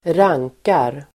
Uttal: [²r'ang:kar]